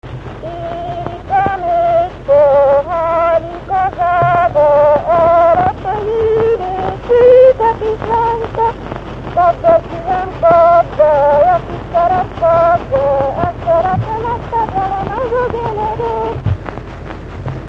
Erdély - Udvarhely vm. - Felsőboldogfalva
Műfaj: Gyermekjáték
Stílus: 7. Régies kisambitusú dallamok
Szótagszám: 6.6.6.6
Kadencia: 3 (3) X 1